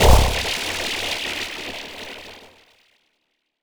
Synth Impact 10.wav